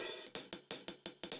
Index of /musicradar/ultimate-hihat-samples/170bpm
UHH_AcoustiHatA_170-03.wav